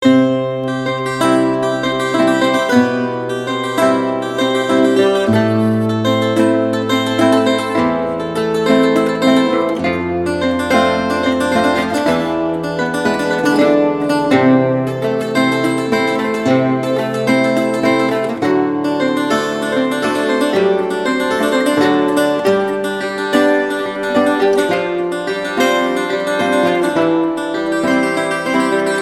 • Качество: 128, Stereo